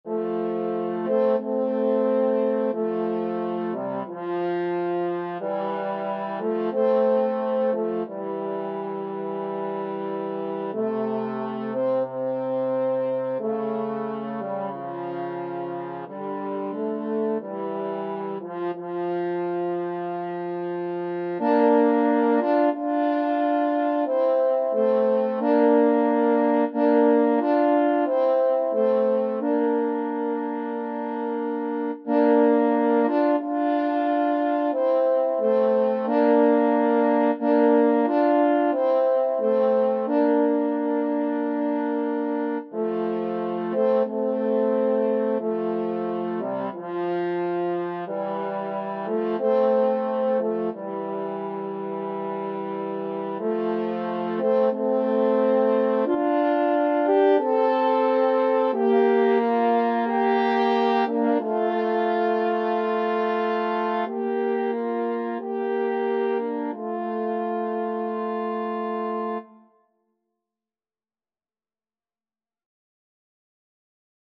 Free Sheet music for French Horn Duet
F major (Sounding Pitch) C major (French Horn in F) (View more F major Music for French Horn Duet )
=180 Largo
French Horn Duet  (View more Easy French Horn Duet Music)
Classical (View more Classical French Horn Duet Music)